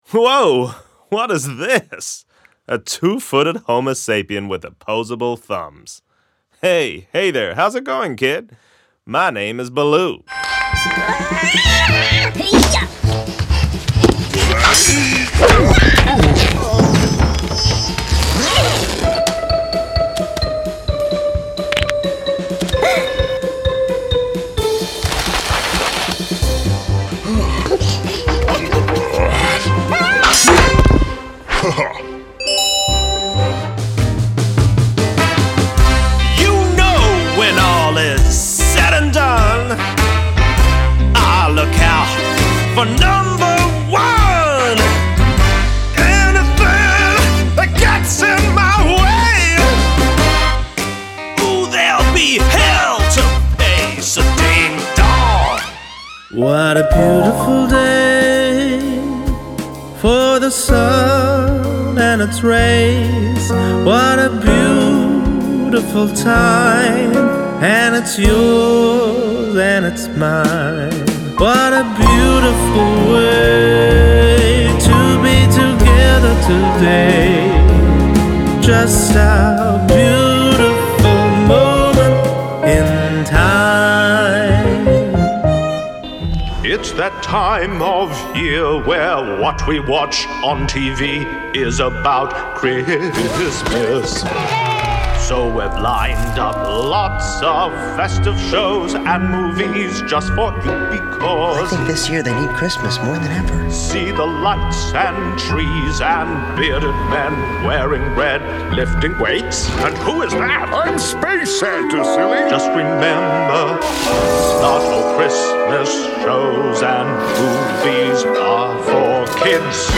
Voice Samples: Character Reel
EN NZ
male